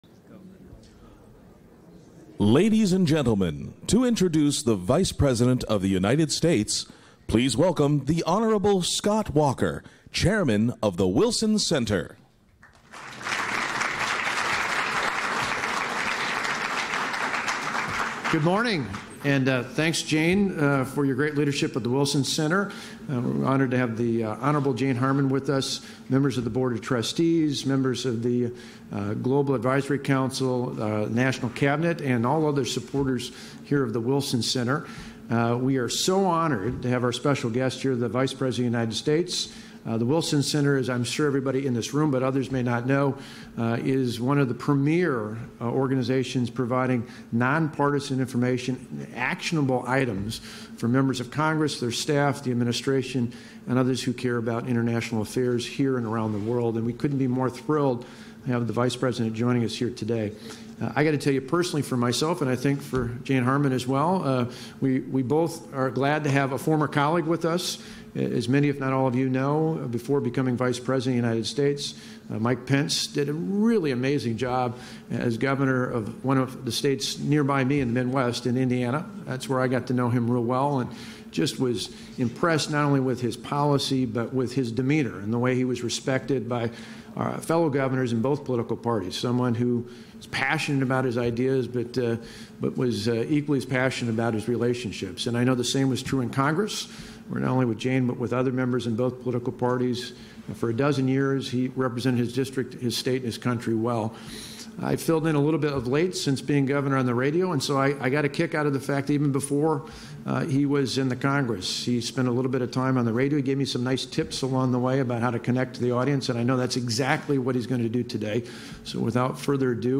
美国副总统彭斯周四在威尔逊国际学者中心发表了有关美中关系的演说。彭斯在演说中谈到了美中贸易问题、批评了中国的人权和宗教状况、中国对外军事扩张，并且表达了对香港和平抗议活动以及台湾民主的支持。